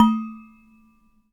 bell_small_muted_02.wav